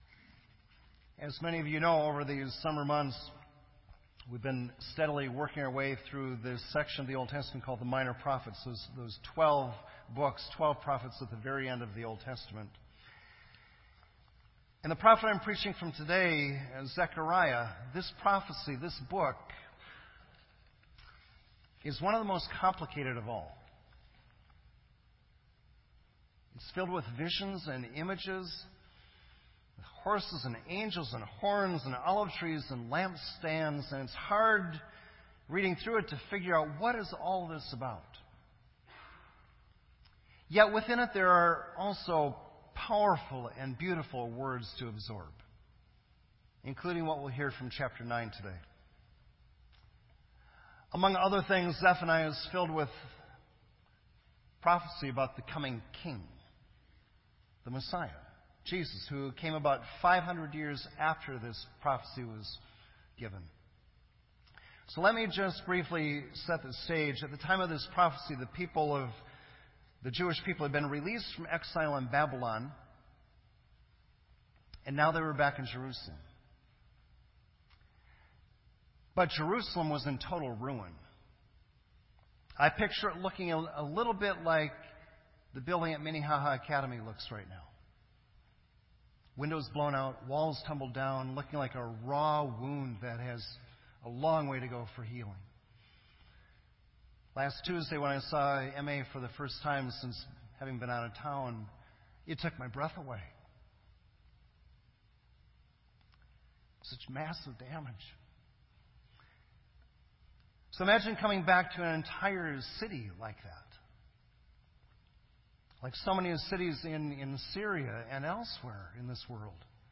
Genre: Sermon.